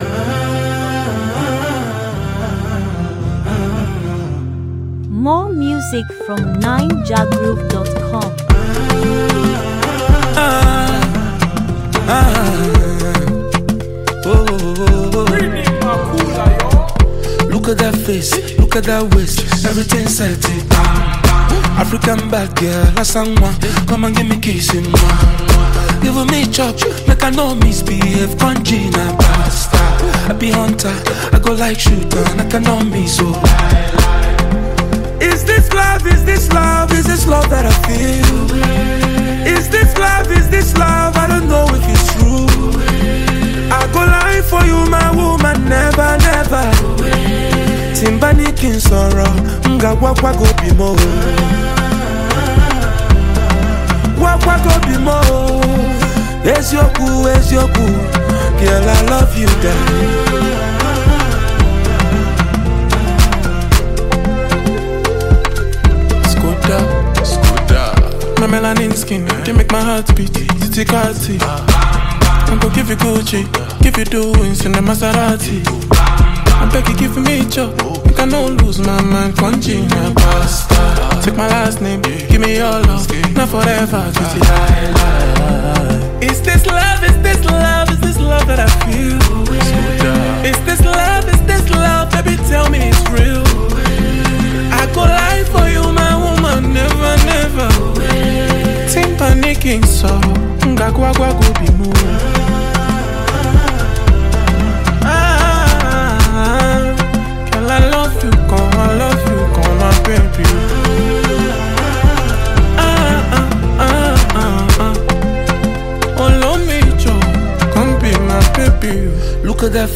warm, textured record